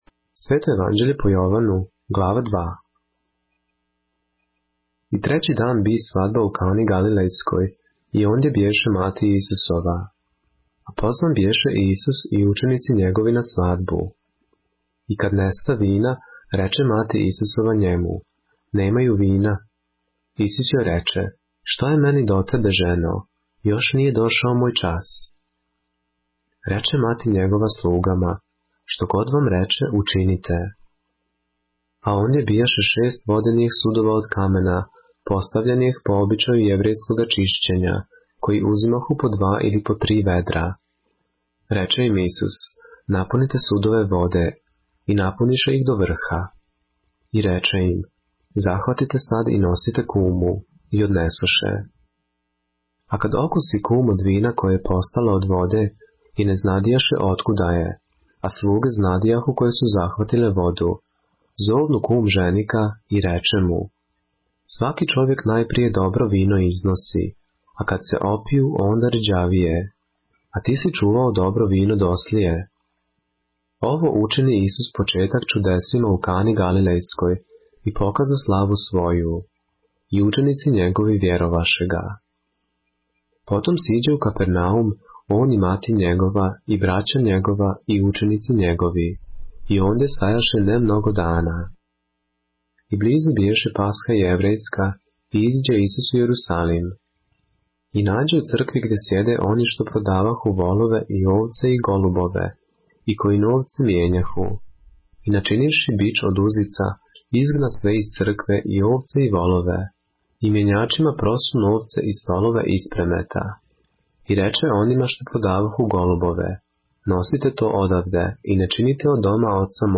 поглавље српске Библије - са аудио нарације - Христос Створитељ Универзума - Jovanu - ЈОВАН 2